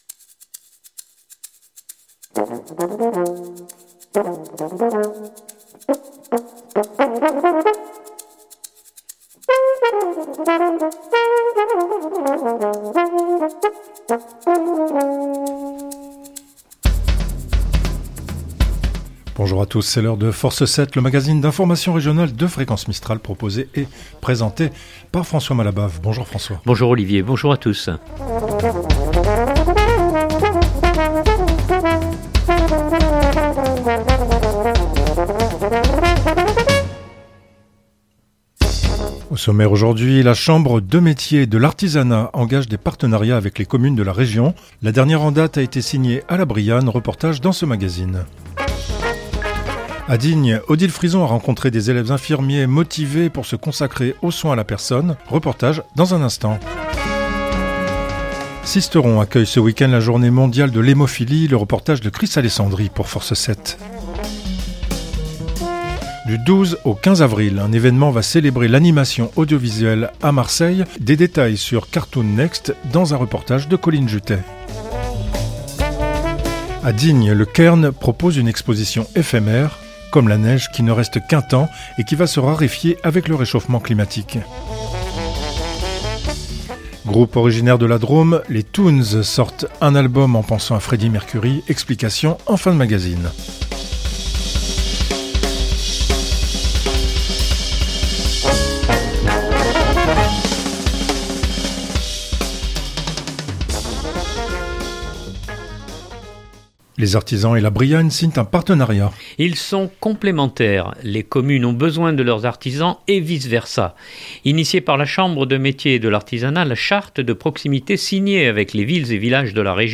Reportage dans ce magazine.